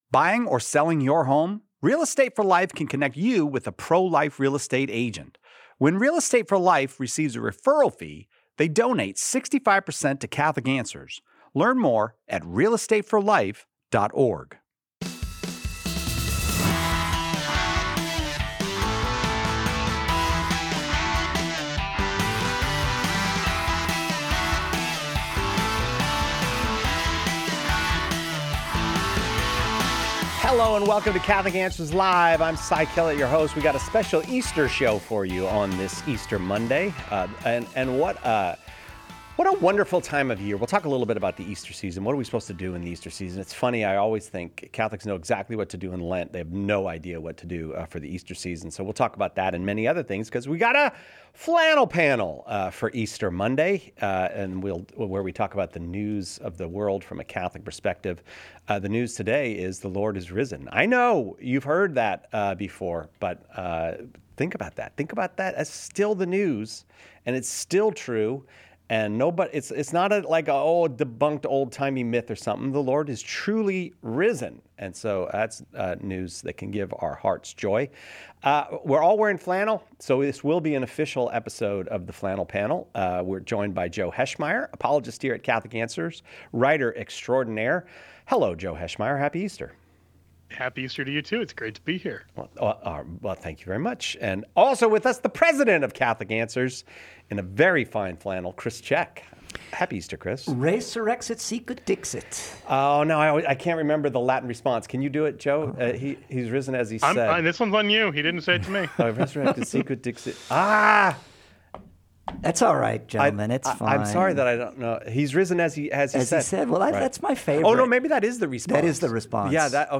Whether you’re a convert, cradle Catholic, or someone exploring the faith, this lively episode offers encouragement, challenge, and practical takeaways for living the Resurrection all year long.